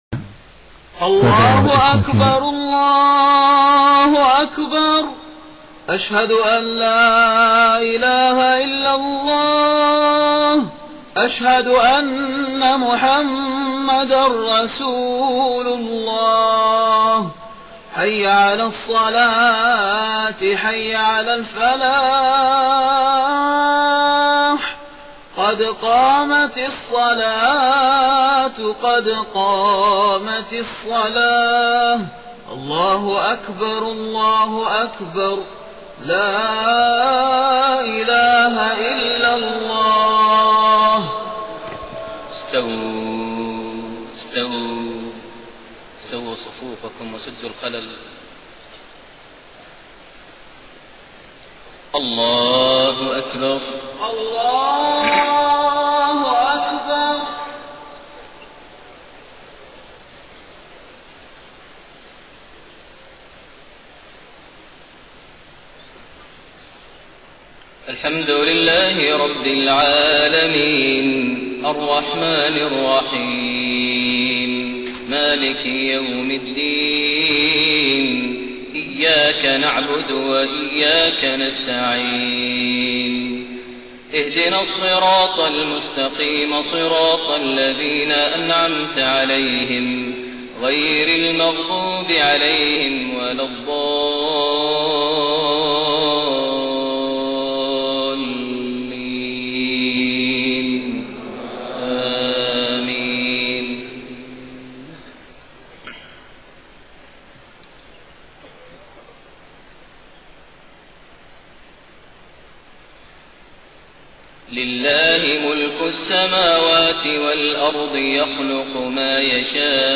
صلاة المغرب 18 ذو الحجة 1432هـ خواتيم سورة الشورى 49-53 > 1432 هـ > الفروض - تلاوات ماهر المعيقلي